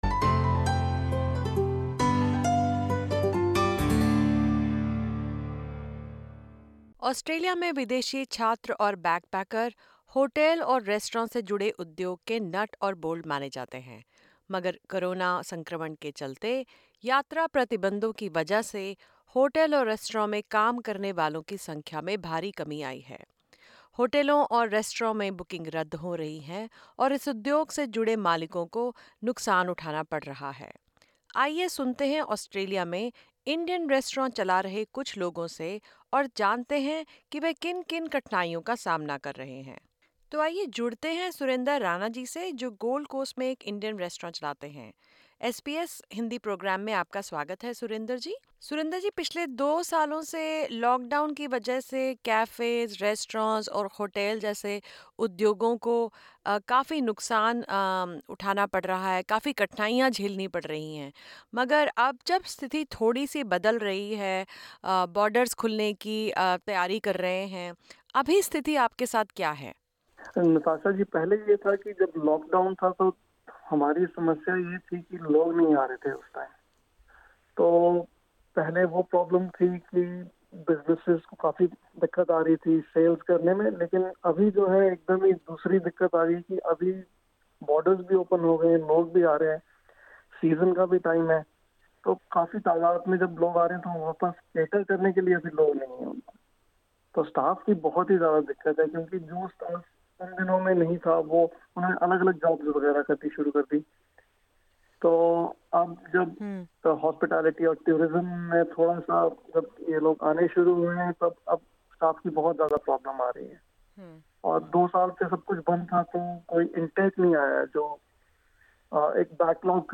Restaurant owners are unable to find staff to handle a full house and are avoiding event bookings. Indian restaurant owners explain how difficult it is to run their businesses now that restrictions have been removed.